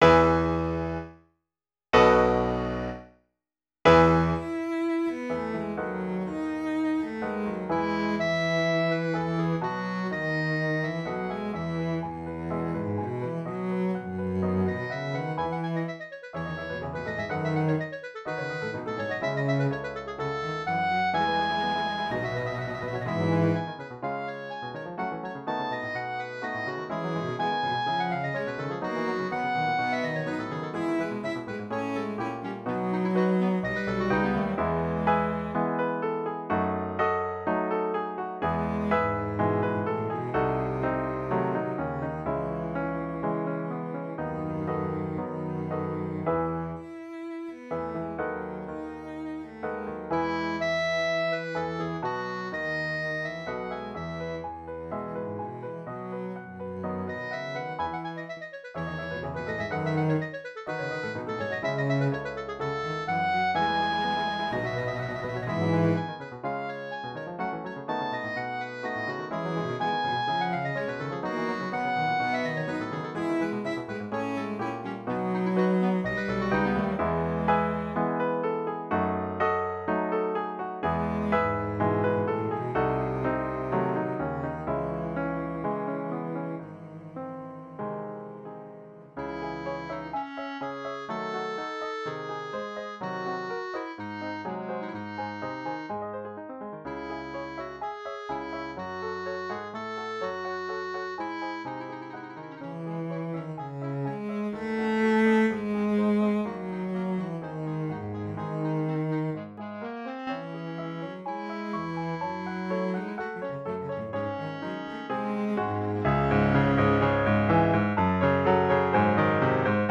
para clarinete, cello y piano